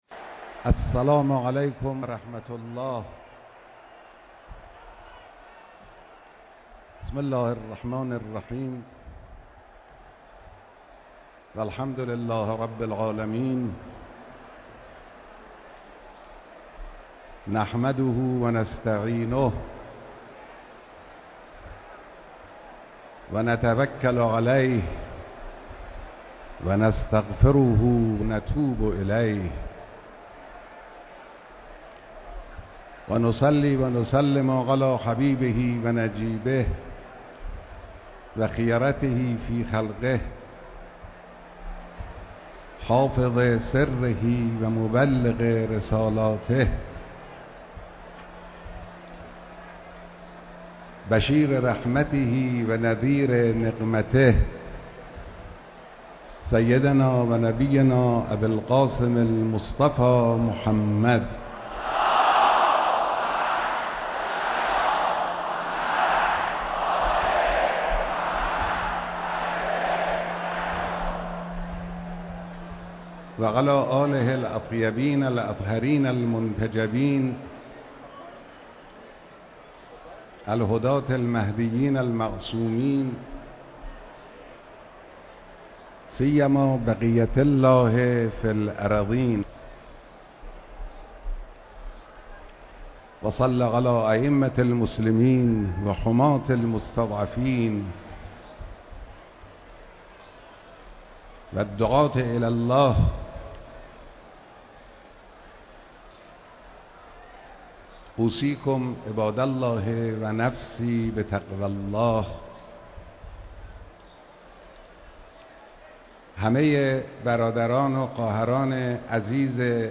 مراسم بیست و يكمين سالگرد رحلت امام خمینی(رض)
خطبه‌ اول نماز جمعه در حرم امام خمینی (ره)